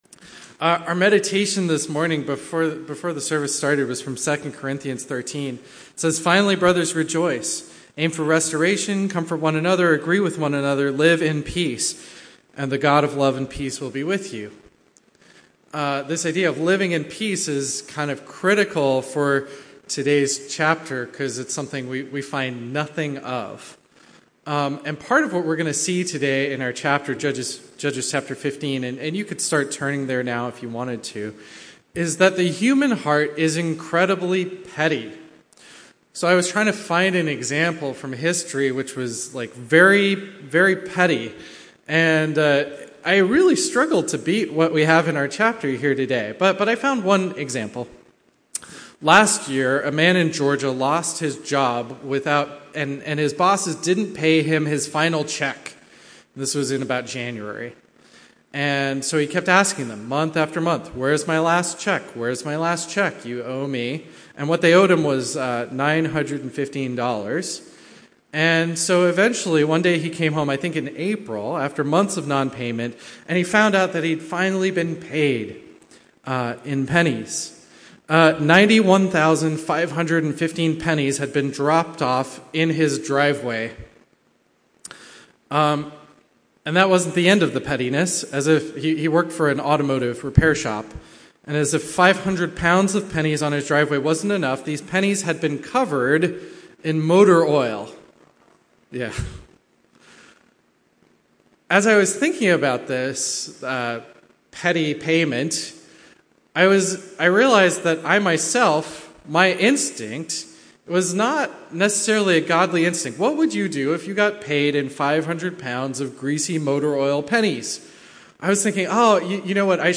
A message from the series "Judges."